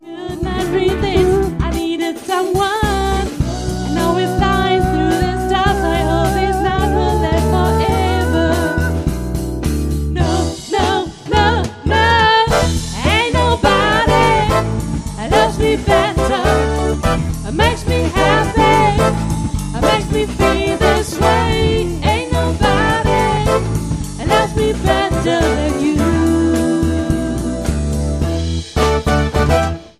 Pop-,Funk-,Soul - Live-Band